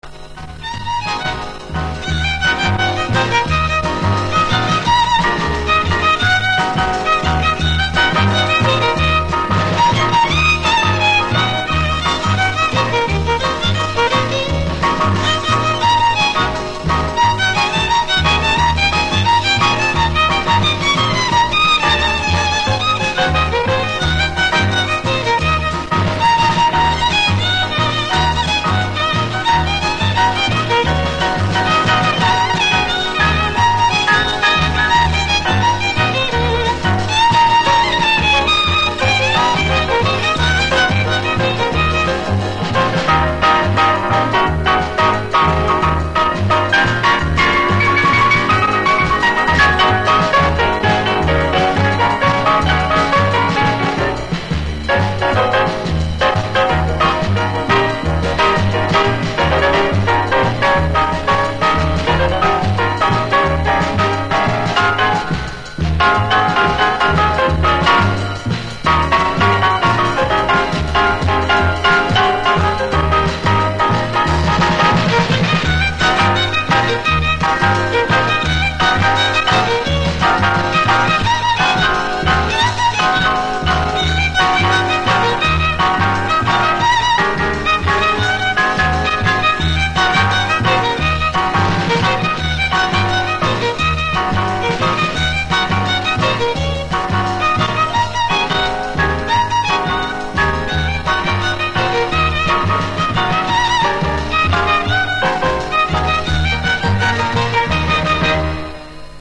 Пять инструменталок (записи 60-х годов)
Это твист на скрипке и фокстрот на органе Хаммонда и аккустических инструментах